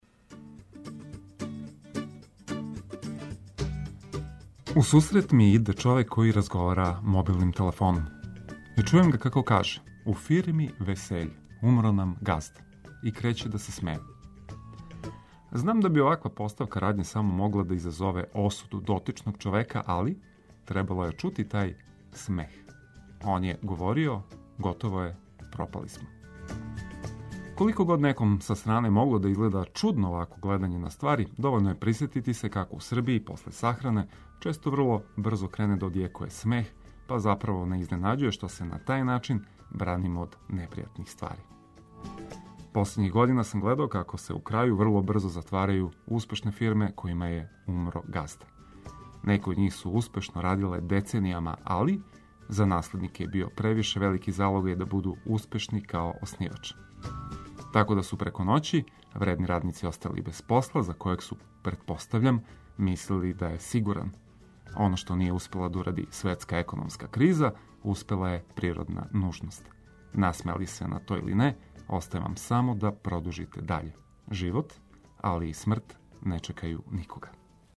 Уз највеће хитове и важне вести дочекајмо спремни нови дан.